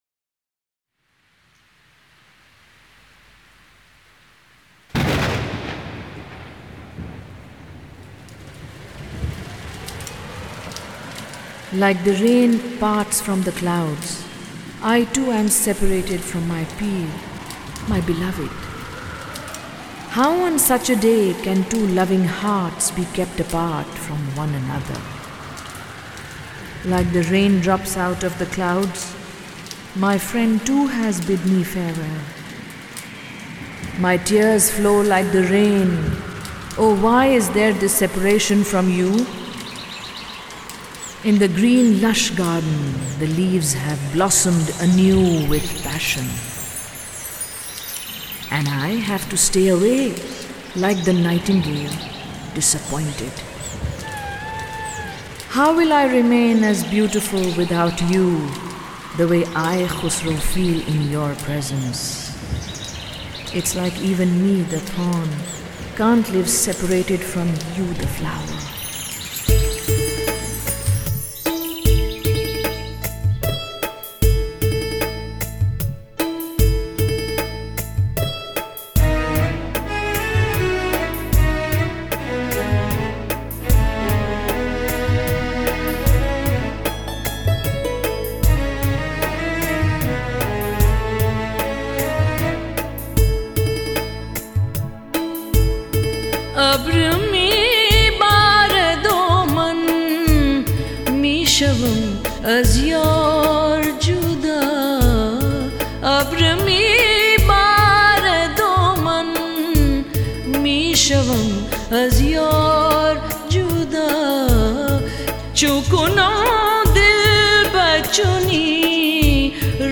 Kalaam/Poetry  ,اردو urdu , farsi (persian)